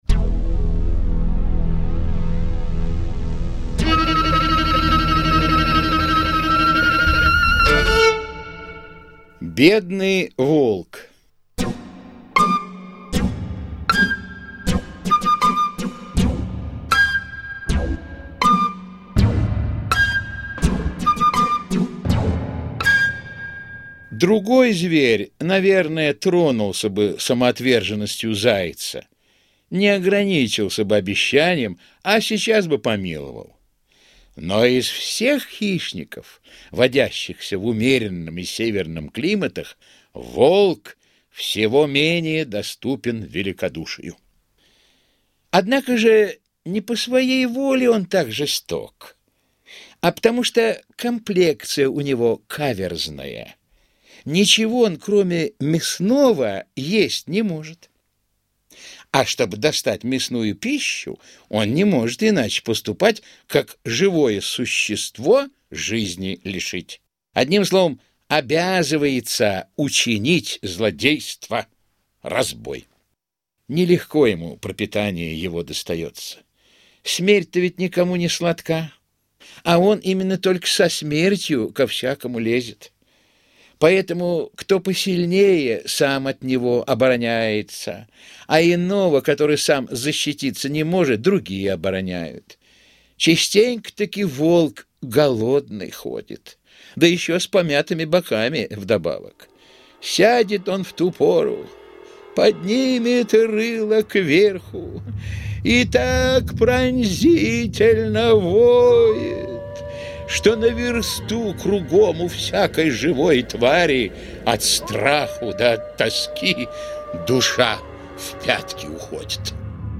Текст читает Евгений Весник.